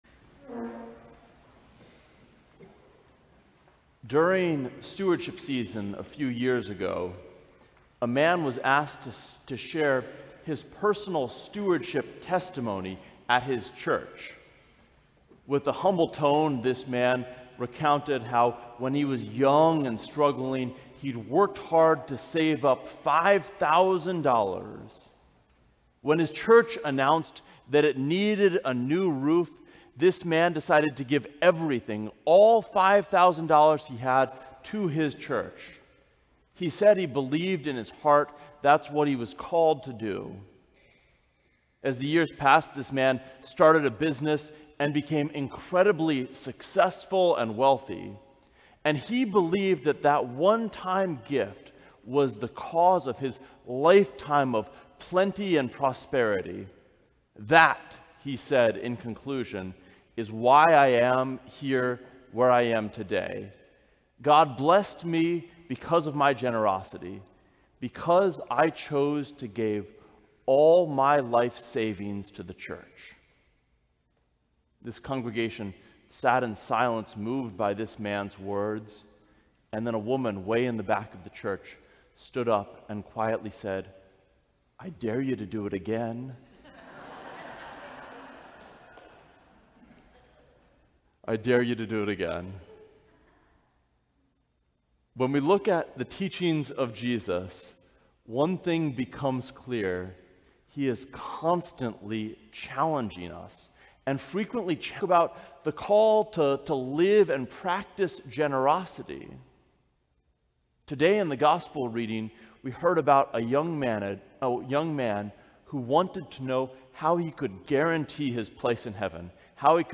Sermon: I dare you to do it again!
Sermons from St. John's Cathedral Sermon: I dare you to do it again!